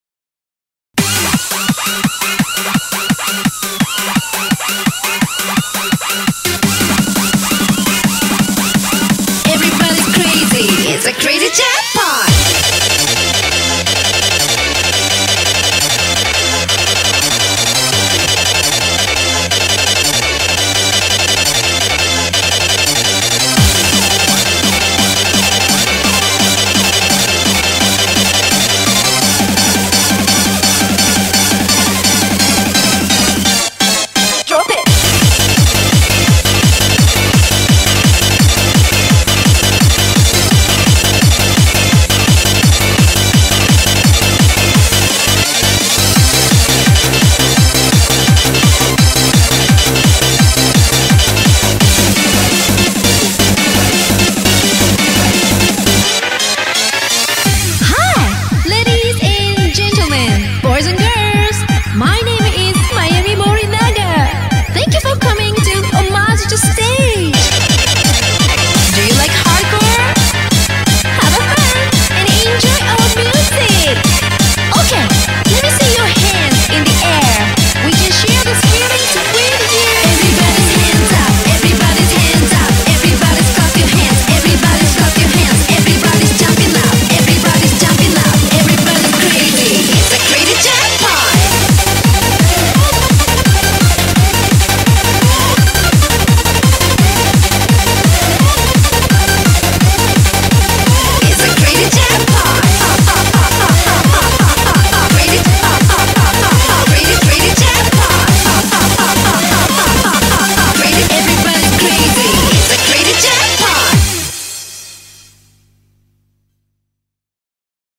BPM170
Audio QualityPerfect (Low Quality)